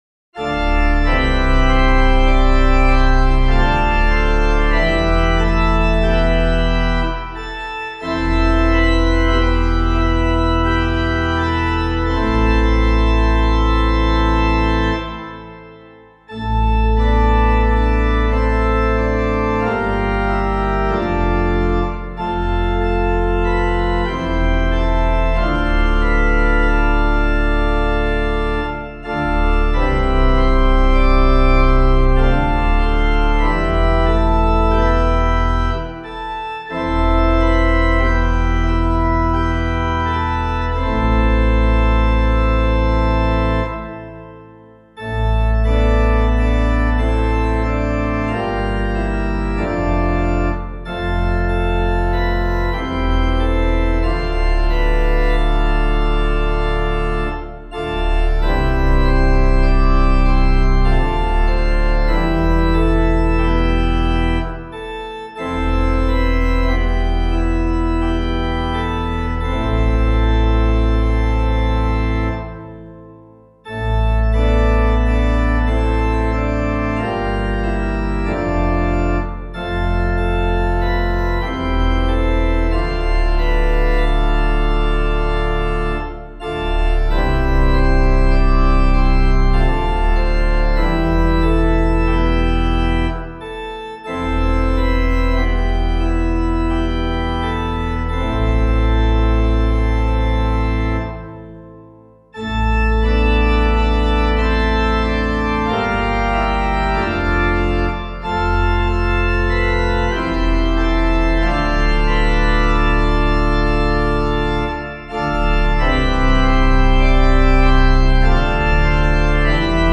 Composer:    Chant, mode I.
organ